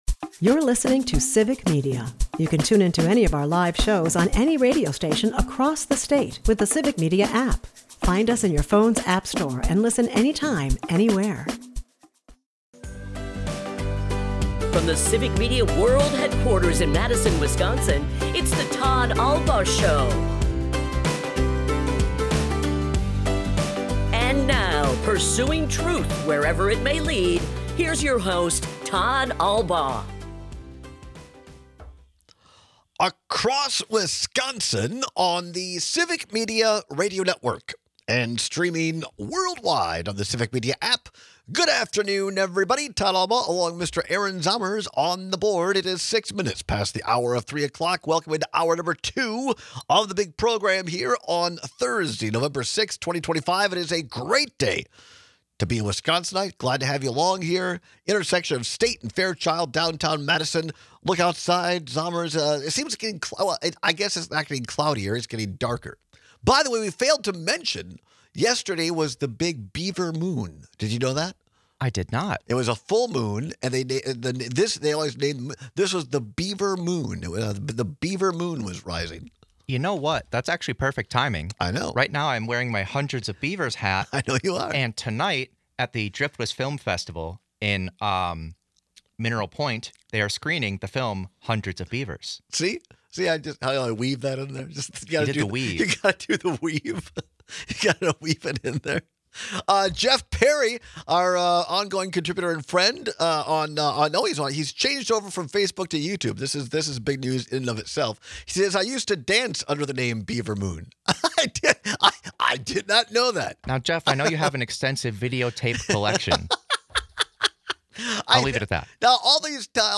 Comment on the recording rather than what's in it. People have strong opinions about how their eggs are cooked, and we take your calls and texts to hear a few of them.&nbsp